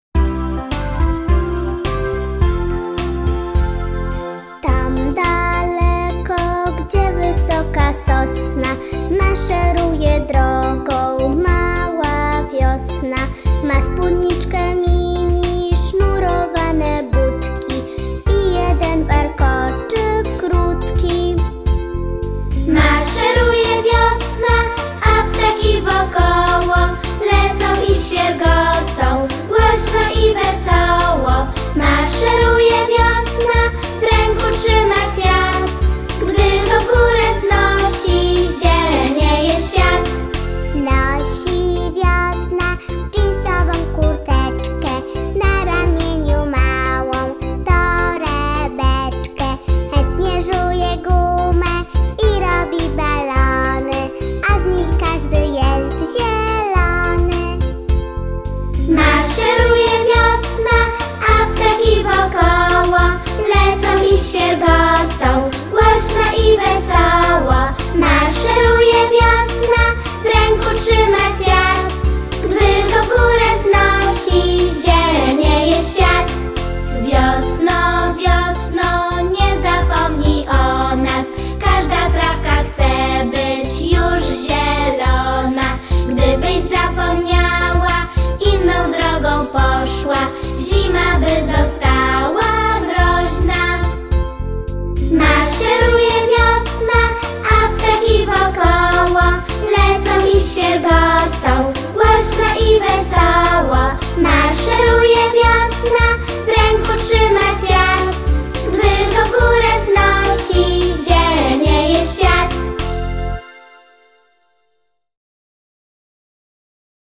piosenka Sówki [4.47 MB]